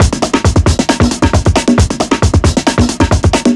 Manic Break 135.wav